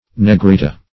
Search Result for " negrita" : The Collaborative International Dictionary of English v.0.48: Negrita \Ne*gri"ta\, n. [Sp., blackish, fem. of negrito, dim. of negro black.]
negrita.mp3